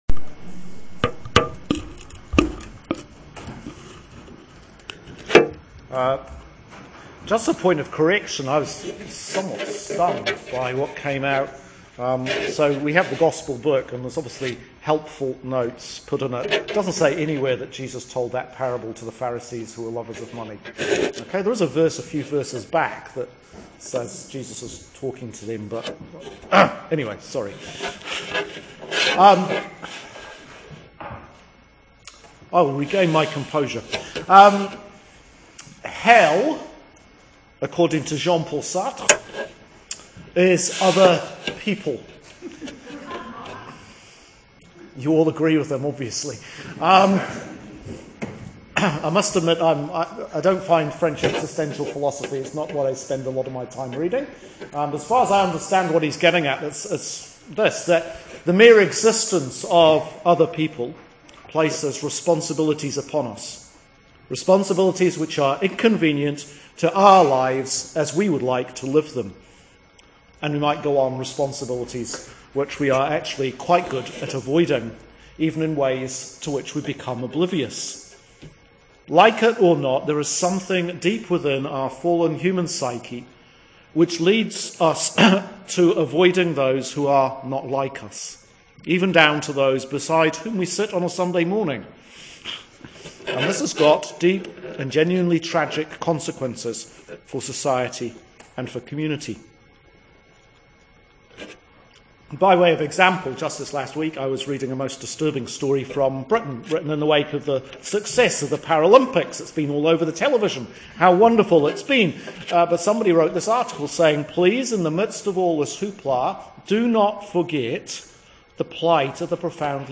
Sermon for Sunday September 25th, 2016, 18 after Trinity, OT26